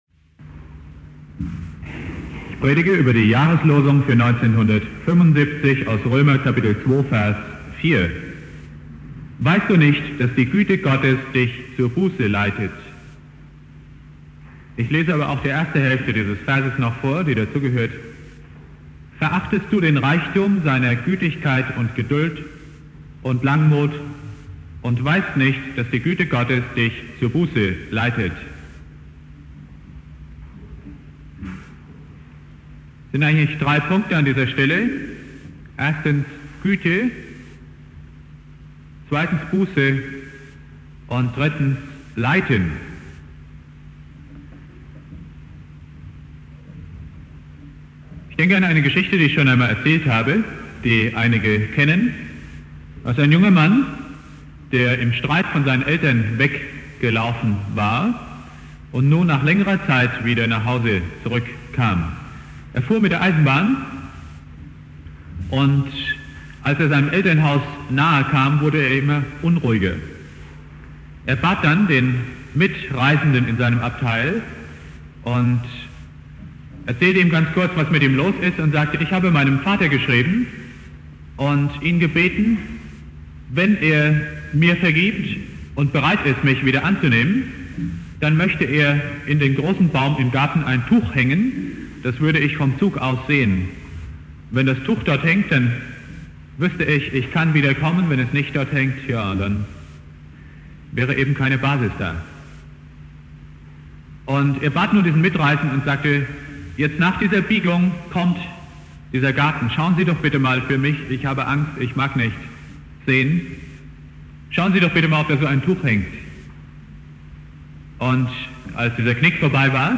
Predigt
Neujahr